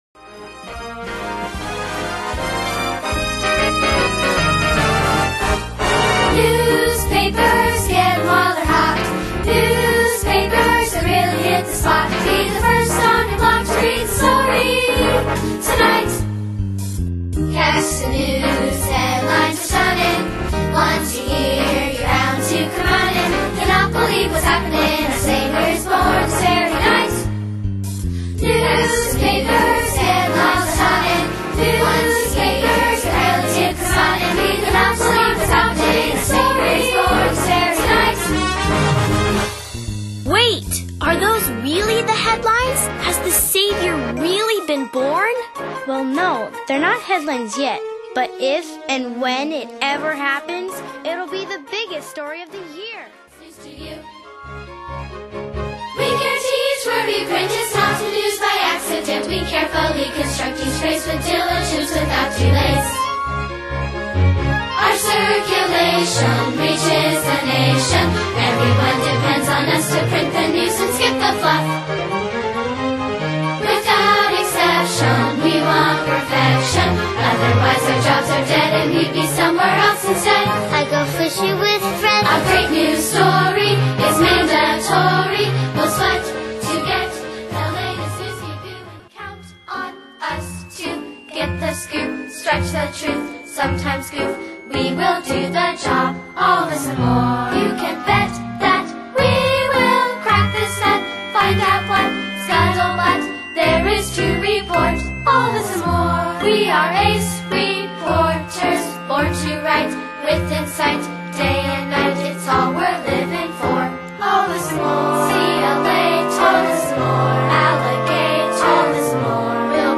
ALL SONGS PREVIEW